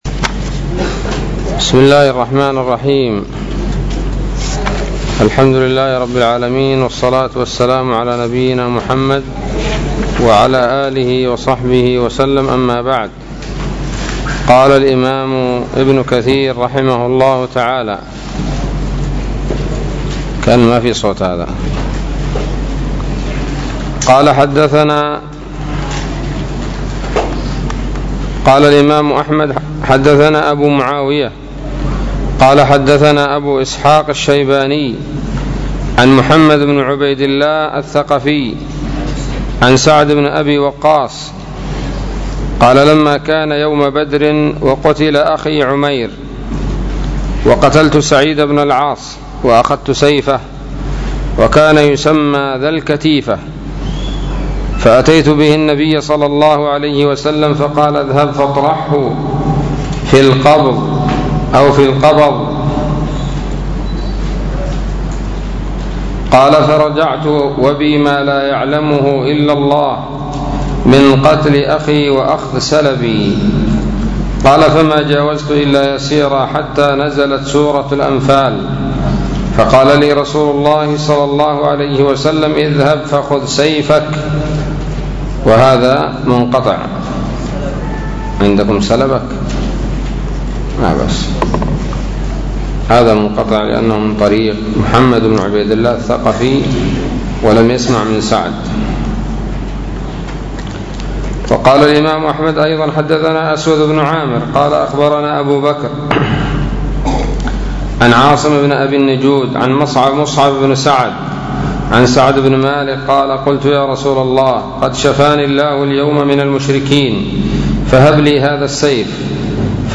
الدرس الثاني من سورة الأنفال من تفسير ابن كثير رحمه الله تعالى